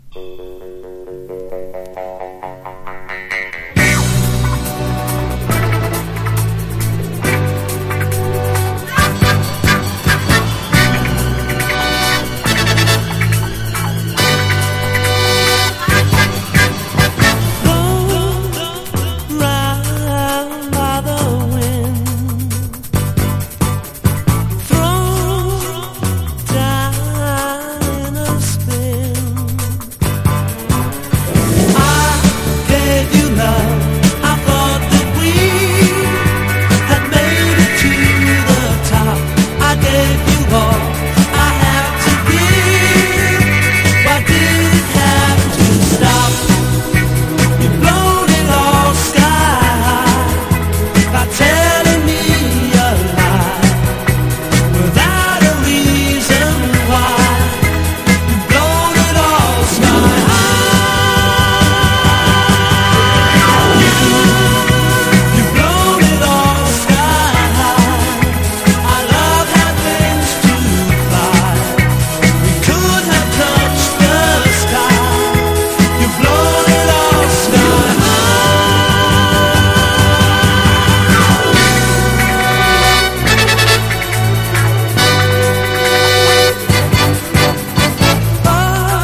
1. 70'S ROCK >